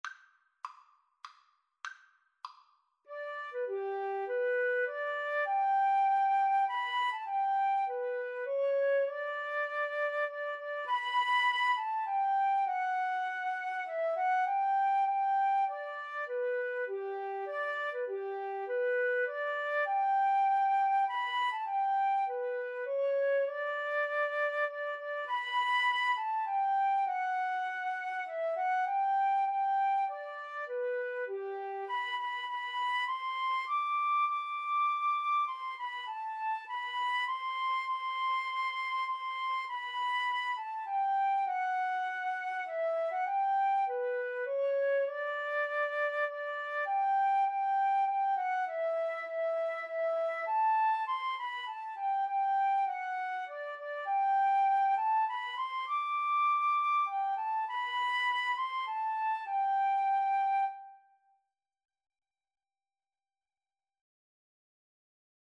3/4 (View more 3/4 Music)
G major (Sounding Pitch) (View more G major Music for Flute Duet )
Flute Duet  (View more Intermediate Flute Duet Music)